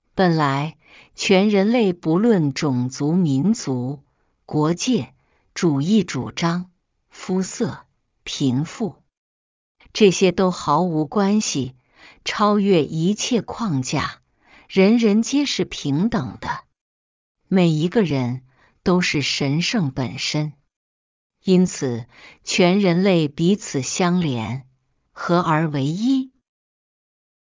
本来の在り方宣言ー中国語(女性).mp3
※ 発音基準：普通话（標準中国語）